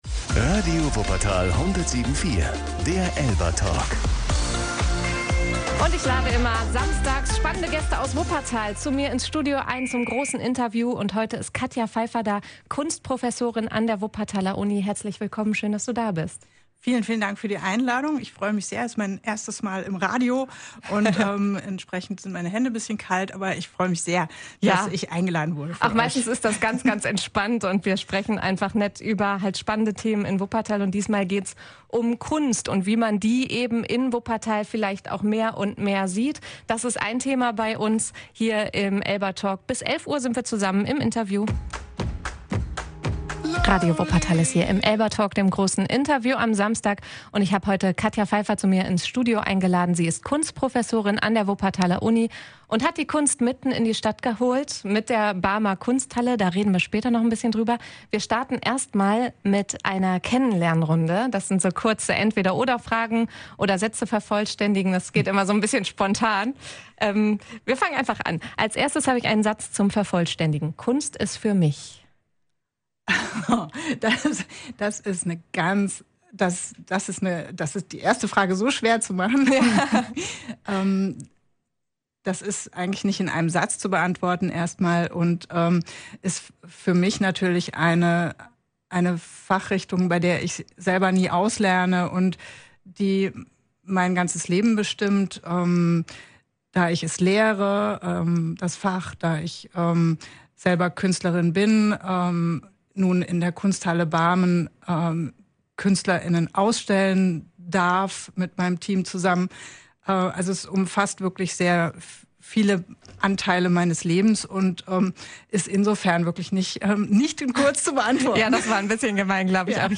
ELBA-Talk